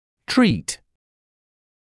[triːt][триːт]лечить; обращаться, вести себя (по отношению к кому-то)